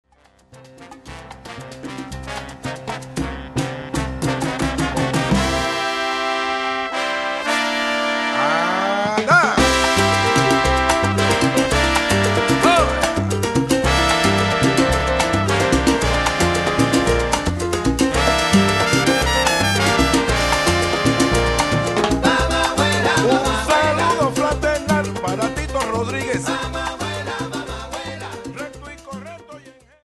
Category: salsa
Style: mambo
Solos: vocal